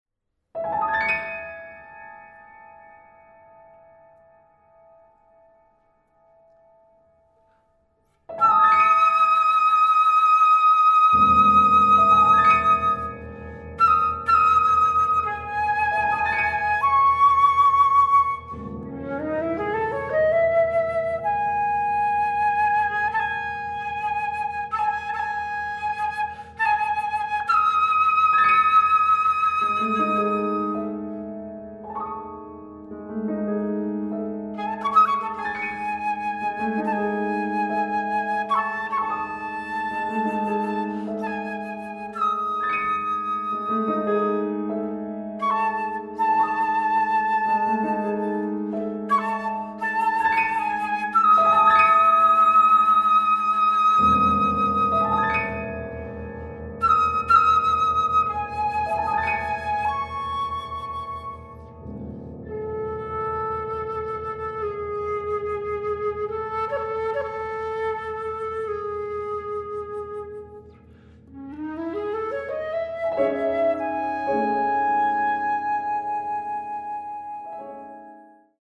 Australian classical music
piano
Sonata for Flute and Piano